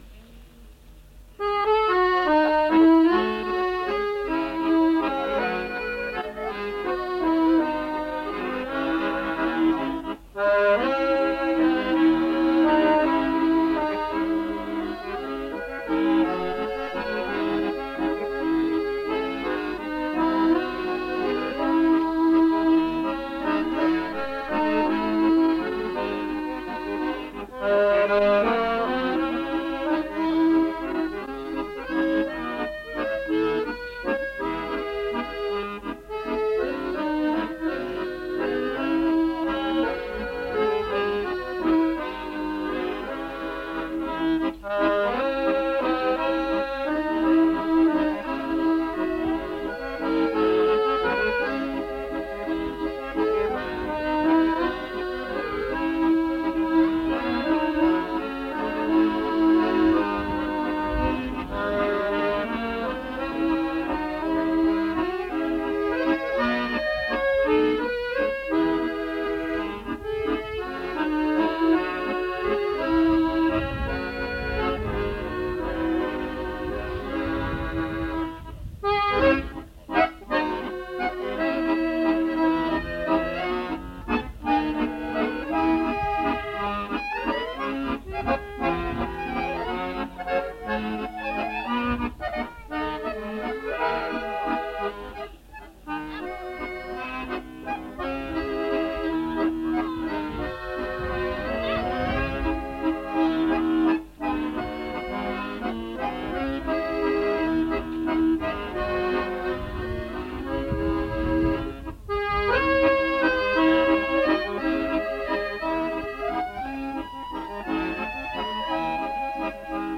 Kaustisen kansanmusiikkijuhlien kyläsoitto oli Nikulassa